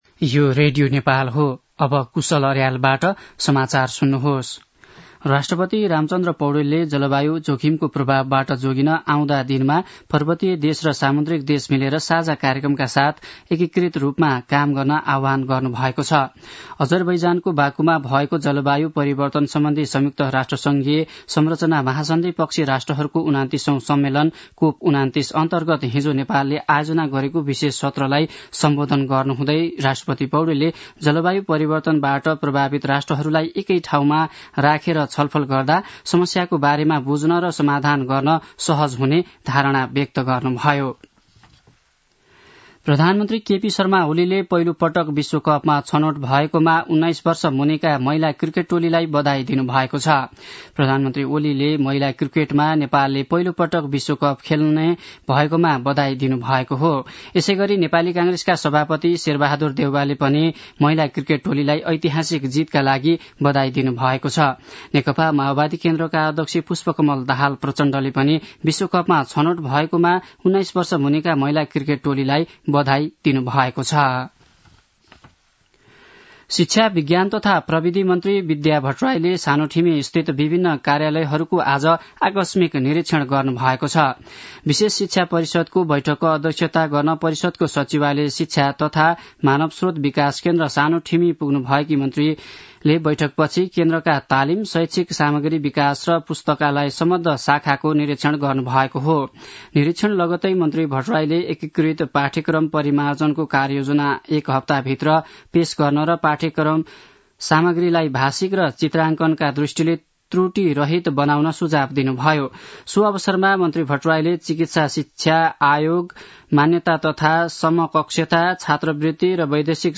दिउँसो १ बजेको नेपाली समाचार : ३० कार्तिक , २०८१
1-pm-nepali-news-1-1.mp3